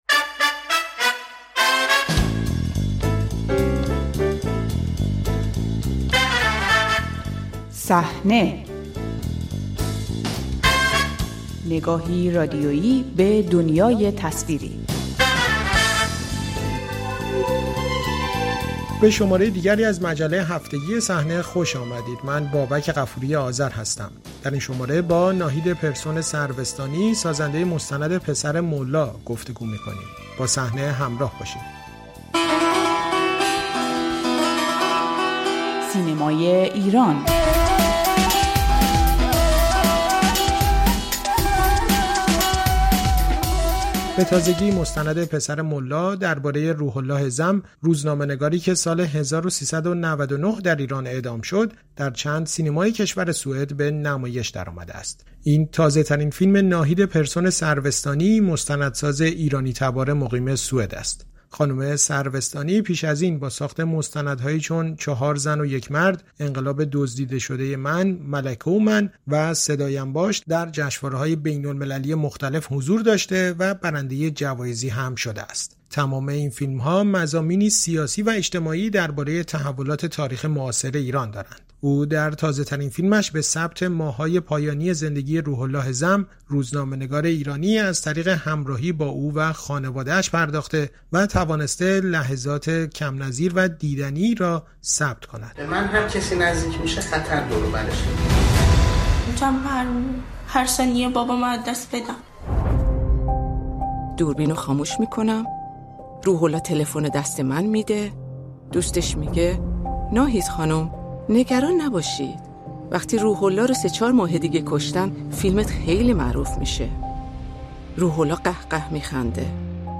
ماه‌های پایانی زندگی روح‌الله زم، روزنامه‌نگاری که سال ۱۳۹۹ اعدام شد، در مستند «پسر مُلا» به کارگردانی ناهید پرسون‌سروستانی ثبت و روایت شده است. با او در این باره گفت‌وگو کرده‌ایم.
گفت‌وگو با ناهید پرسون‌سروستانی درباره مستند «پسر مُلا»